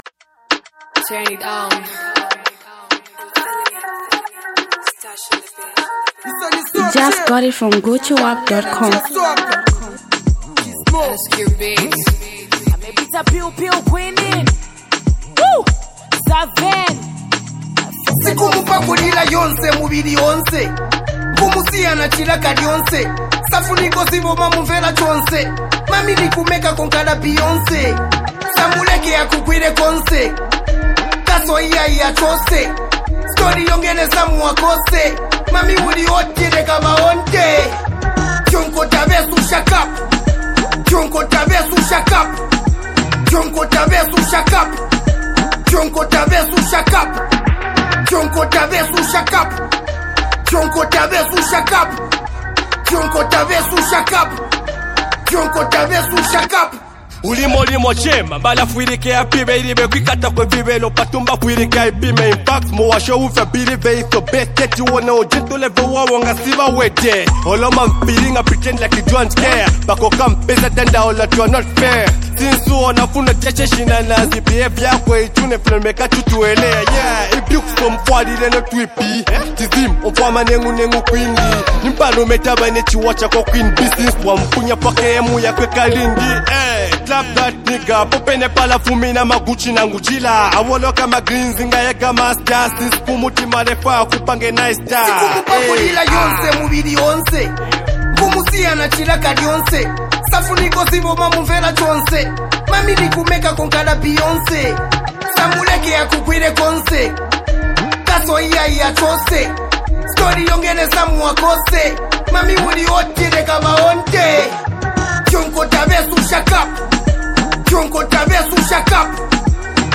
buzzing street record